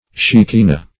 Shekinah \She*ki"nah\, n. [Heb Talmud shek[imac]n[=a]h, fr.